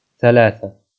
speech-commands